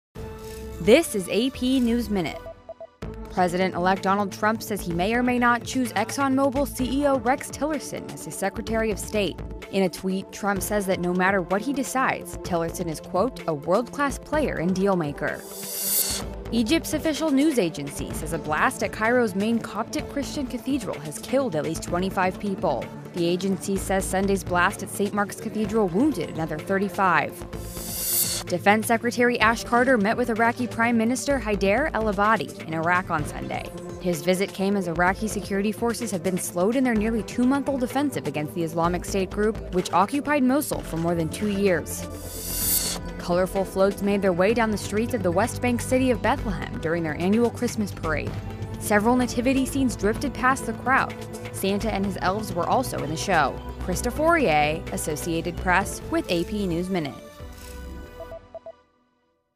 美语听力练习素材:西岸城市伯利恒举行圣诞节游行